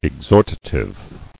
[ex·hor·ta·tive]